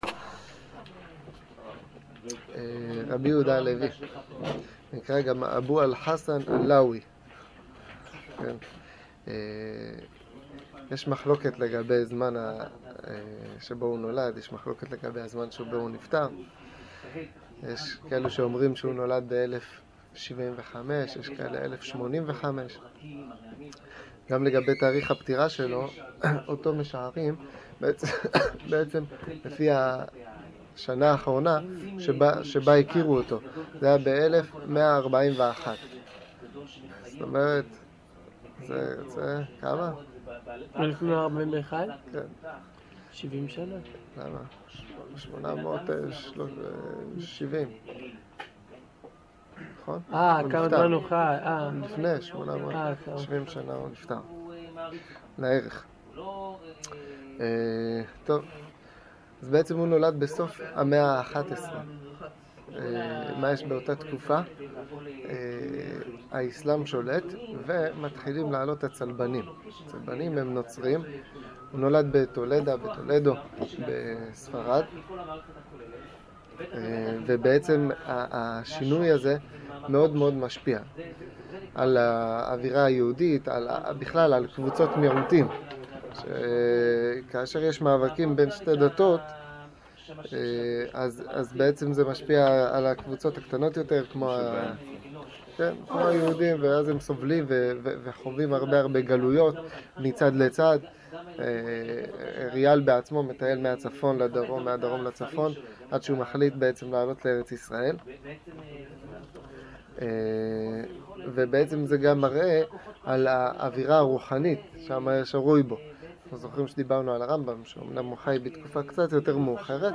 שיעור ריה"ל